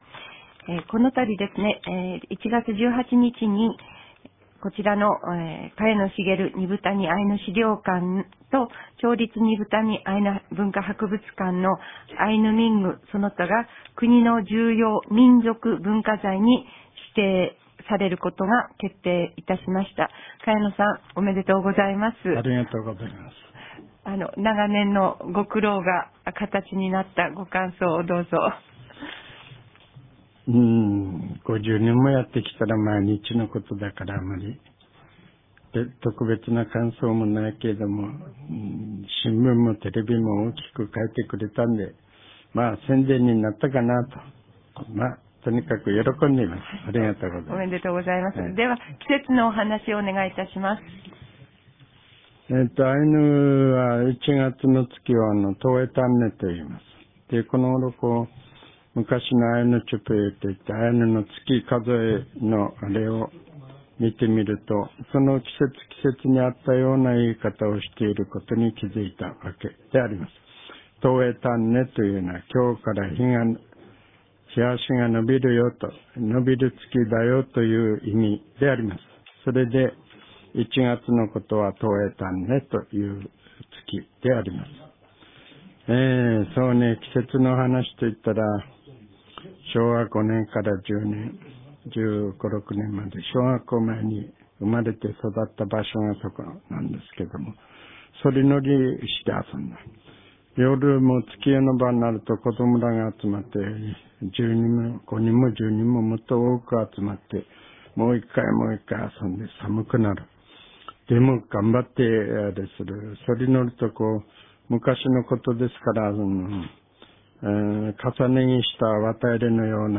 語り手